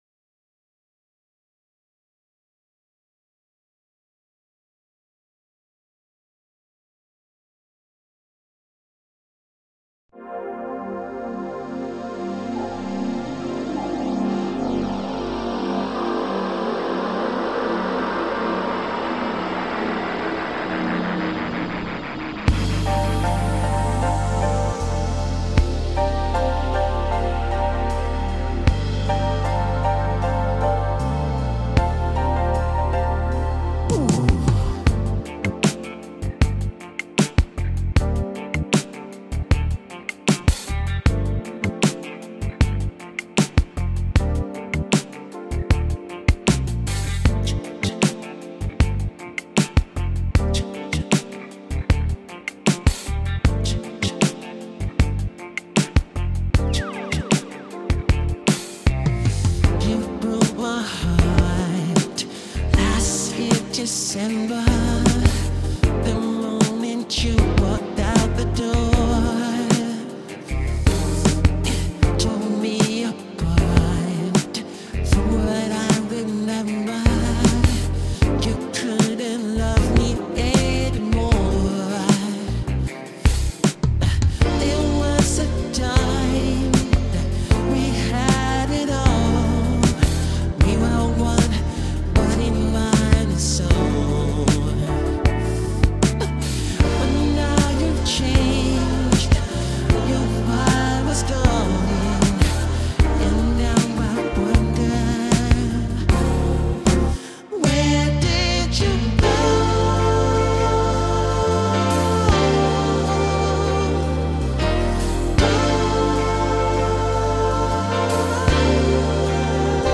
آهنگ هوش مصنوعی